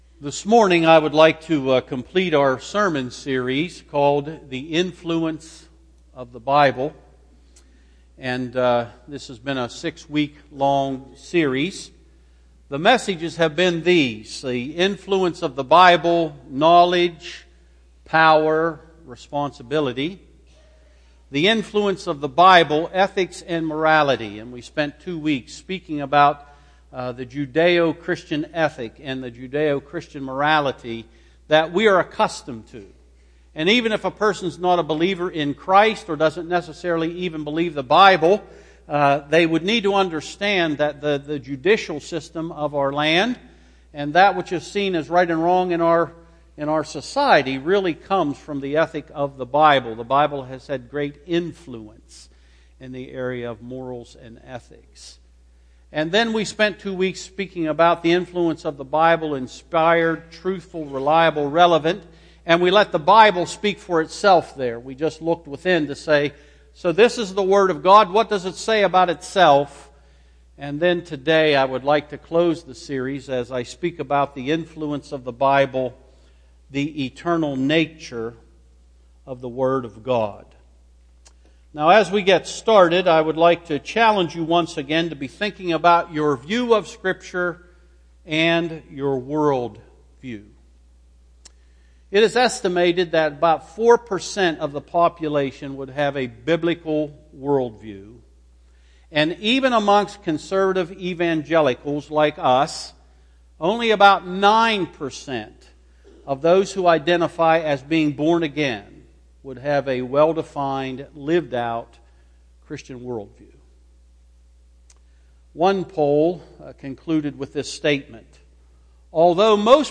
Sermons – North Street Christian Church